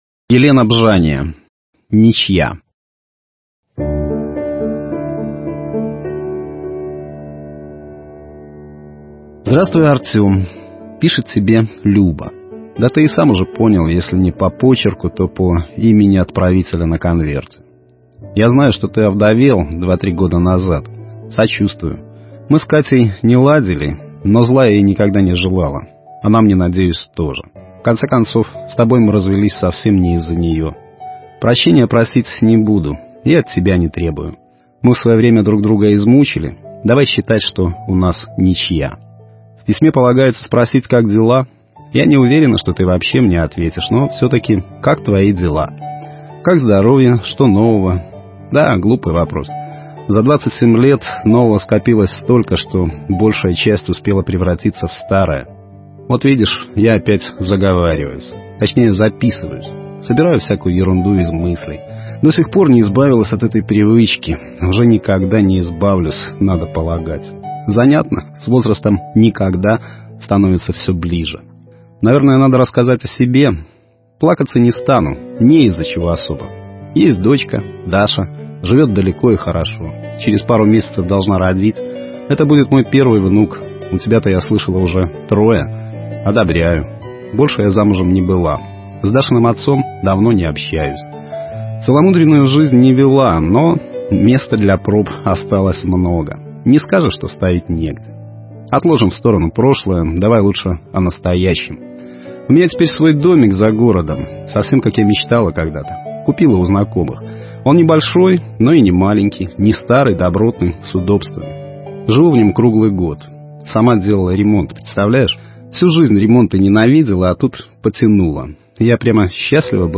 Аудиокниги
Аудио-Рассказы